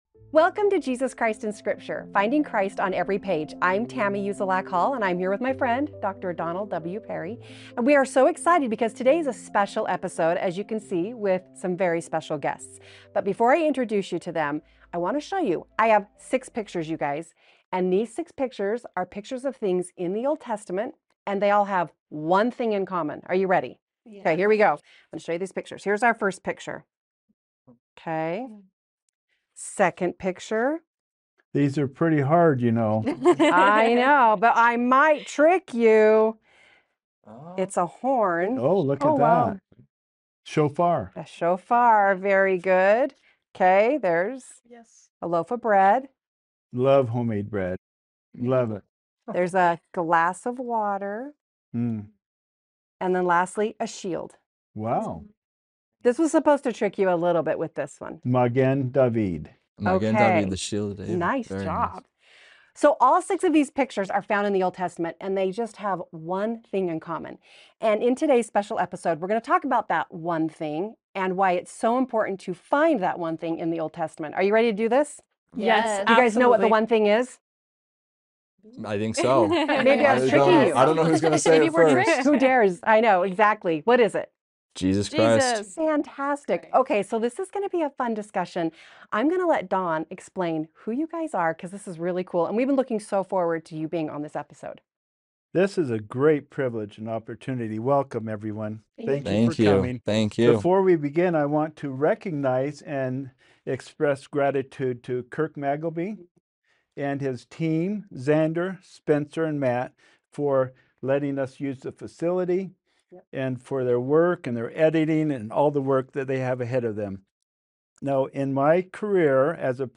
Jesus Christ in the Old Testament Panel Discussion (Ep. 22) Come Follow Me